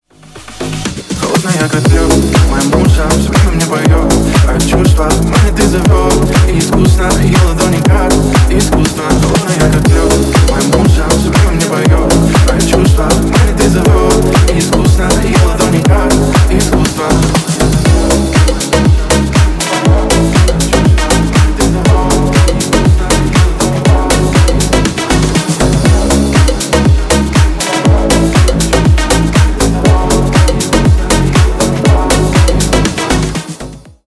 Ремикс
тихие